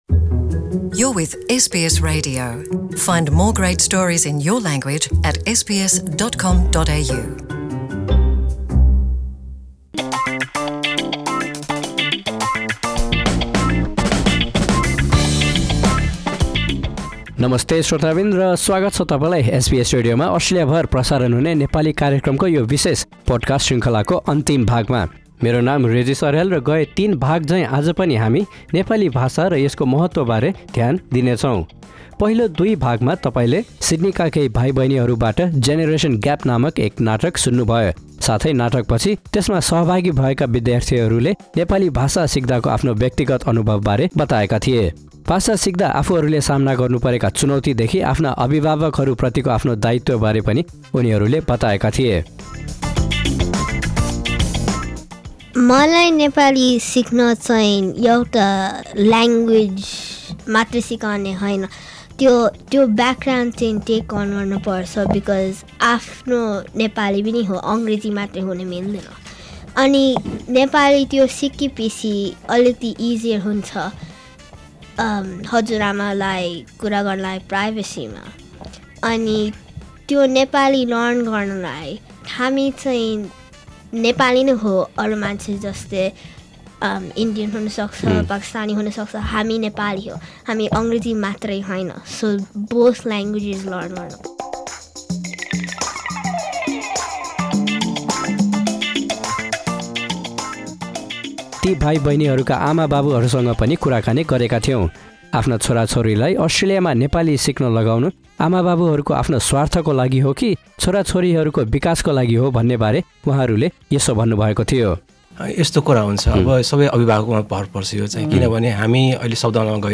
कुराकानी।